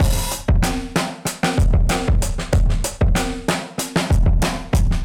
Index of /musicradar/dusty-funk-samples/Beats/95bpm/Alt Sound
DF_BeatB[dustier]_95-03.wav